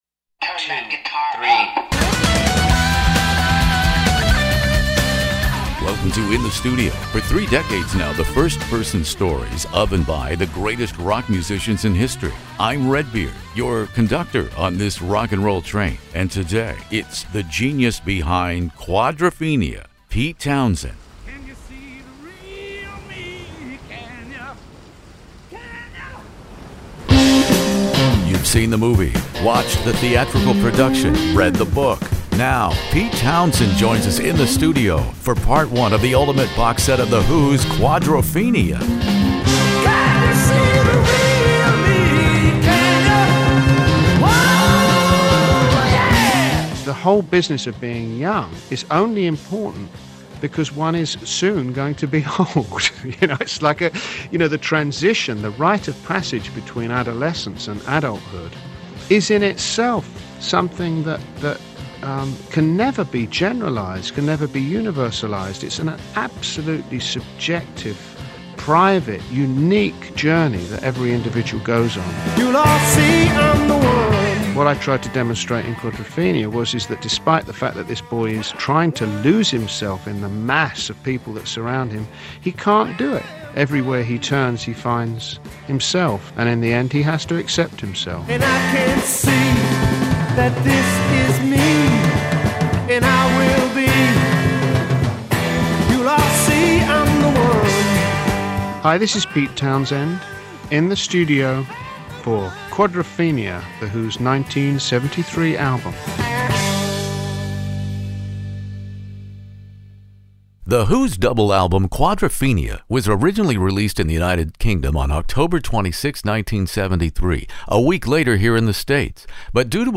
Pete Townshend and Roger Daltrey join me In the Studio in my classic rock interview for the first in a two-part look at the October 1973 magnum opus Quadrophenia by The Who, which interestingly is much more appreciated today than upon its initial release.